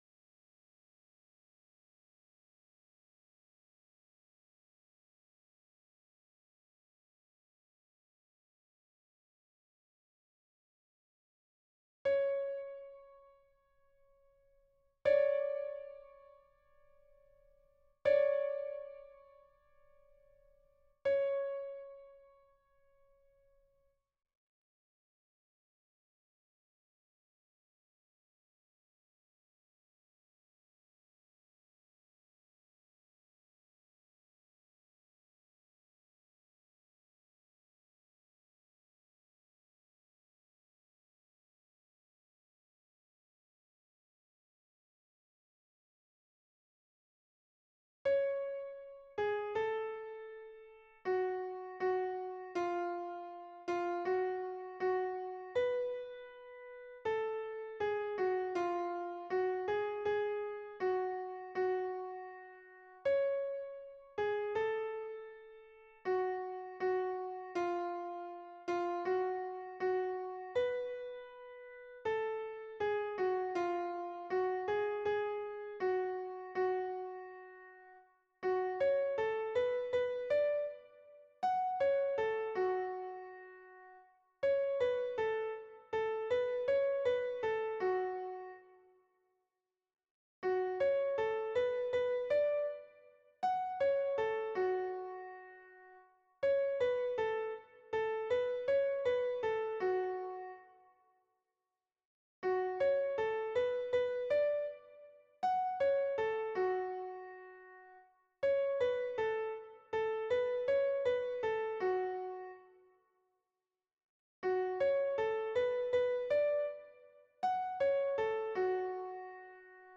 - berceuse traditionnelle norvégienne
MP3 version piano
Soprano